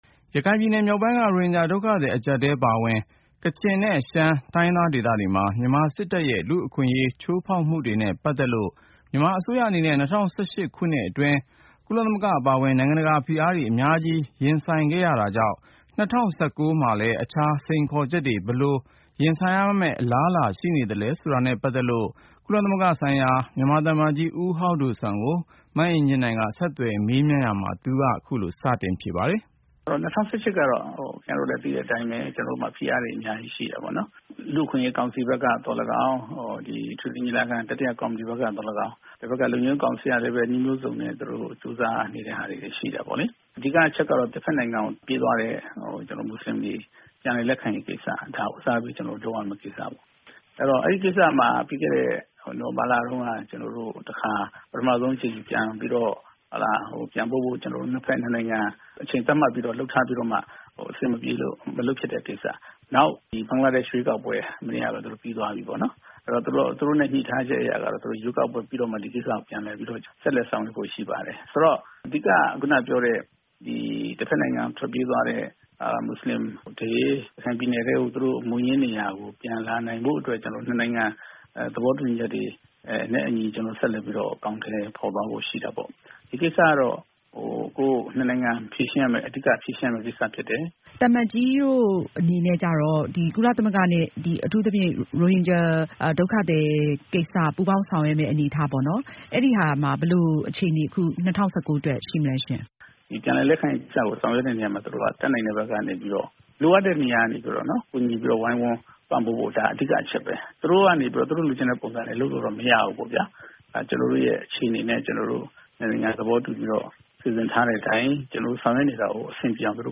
Interview with Hau Do Suan, Permanent Representative of the Republic of the Union of Myanmar to the UN